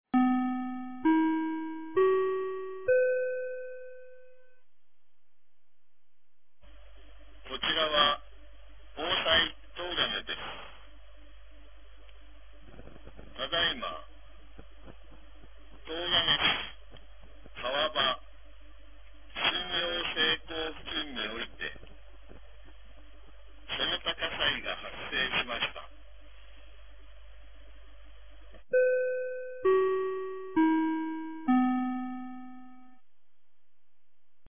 2025年02月01日 14時45分に、東金市より防災行政無線の放送を行いました。